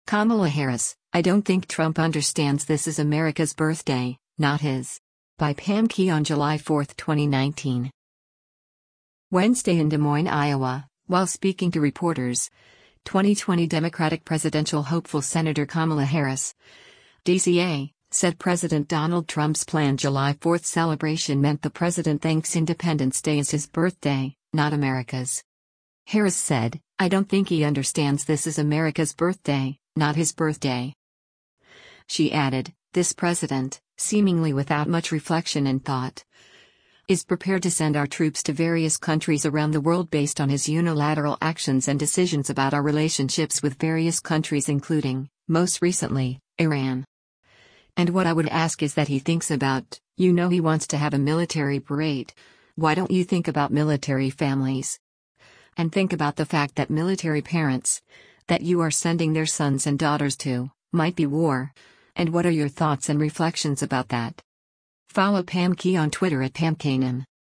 Wednesday in Des Moines, IA, while speaking to reporters, 2020 Democratic presidential hopeful Sen. Kamala Harris (D-CA) said President Donald Trump’s planned July 4 celebration meant the president thinks Independence Day is his birthday, not America’s.